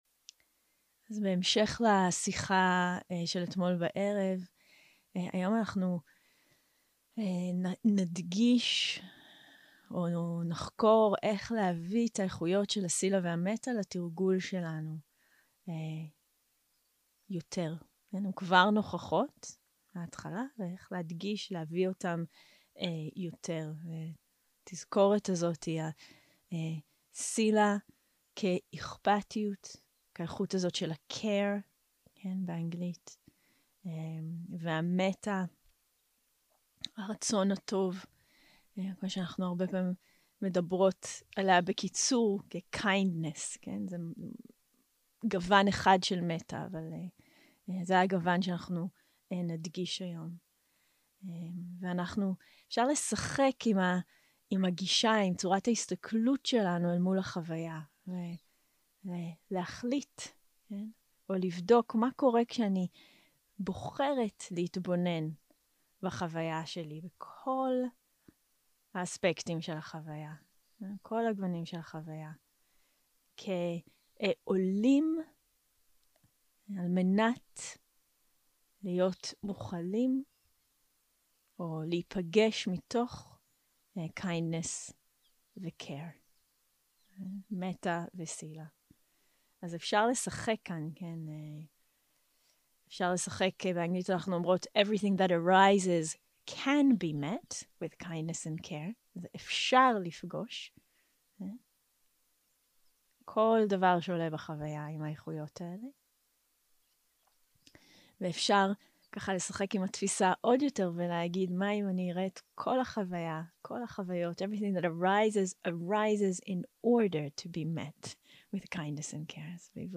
יום 3 - הקלטה 5 - בוקר - הנחיות למדיטציה - הזנה ומענה מיטיב 0:00 0:00 סוג ההקלטה: Dharma type: Guided meditation שפת ההקלטה: Dharma talk language: Hebrew